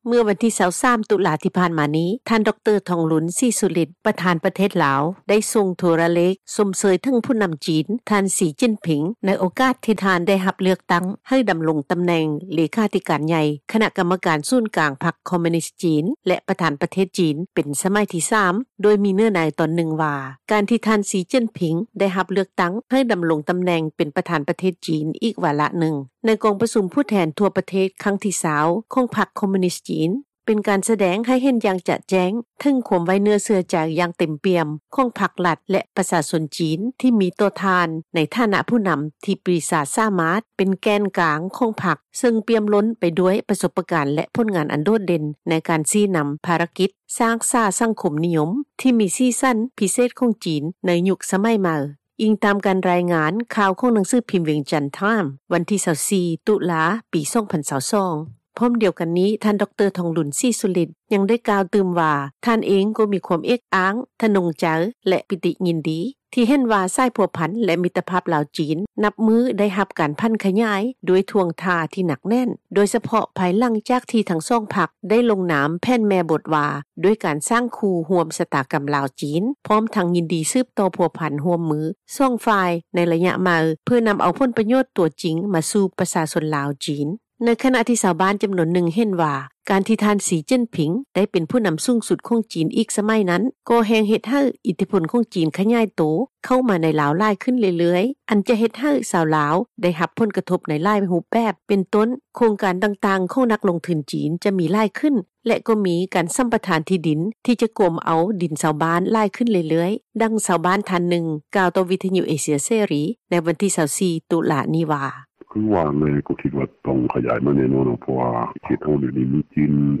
ດັ່ງຊາວບ້ານທ່ານນຶ່ງ ກ່າວຕໍ່ວິທຍຸ ເອເຊັຽເສຣີ ໃນວັນທີ 24 ຕຸລາ ນີ້ວ່າ: